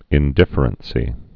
(ĭn-dĭfər-ən-sē, -dĭfrən-)